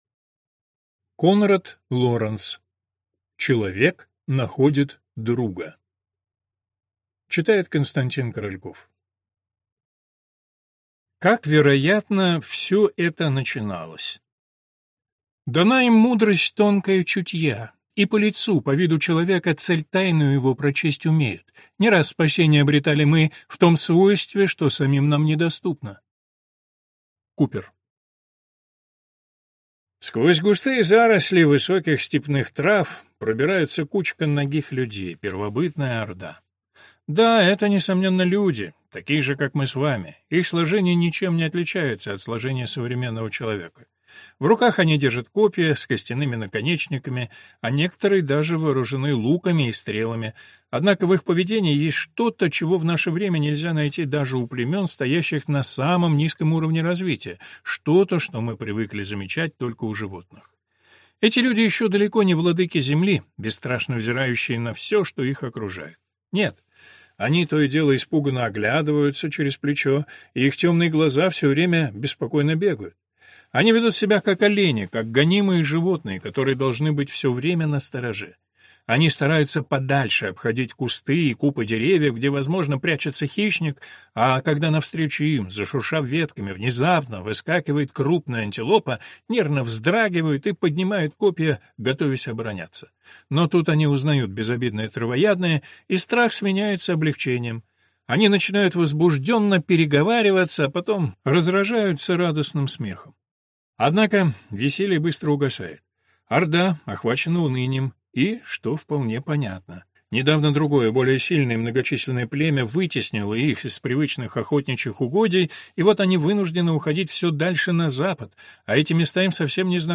Аудиокнига Человек находит друга | Библиотека аудиокниг